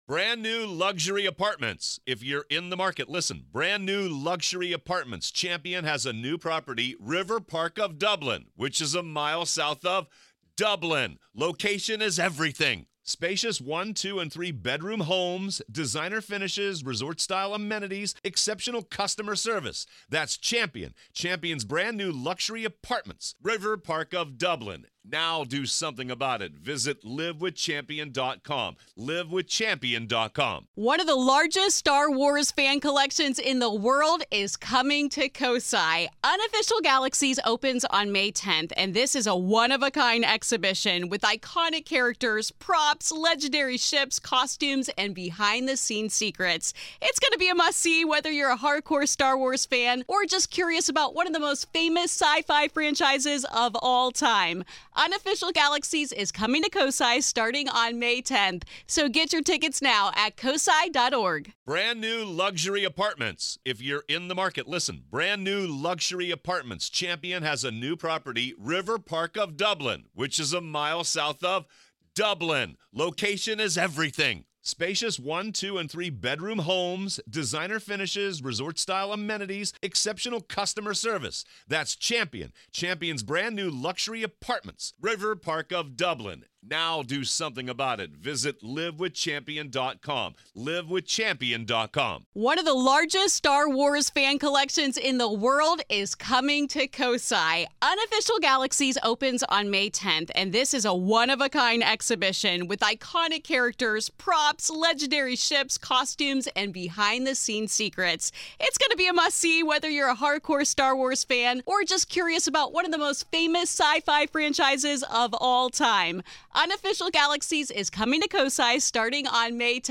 I am joined by you the listener for the new, weekly regular listener show where I go through your emails, DM's and take calls on the UFO topic and related phenomena!